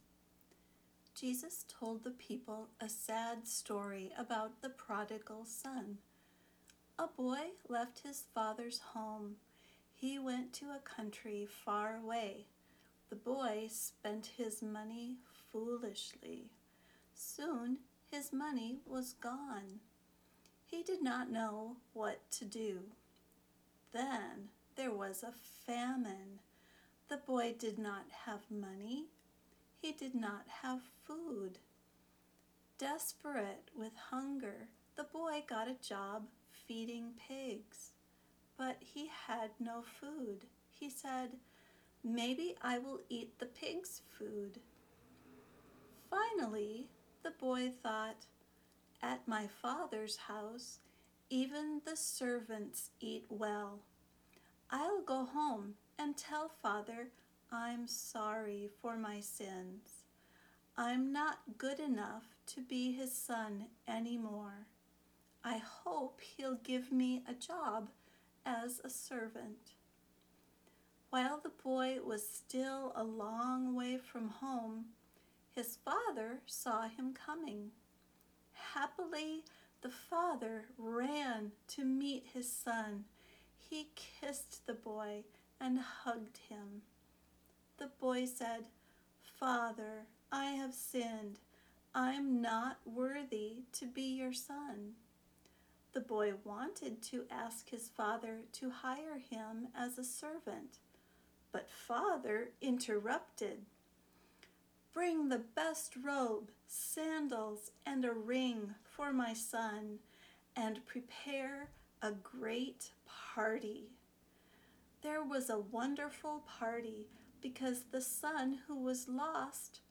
For beginners, the text has more simple vocabulary and verbs. It is also recorded at a slower speed.
Prodigal-son-easy-english.mp3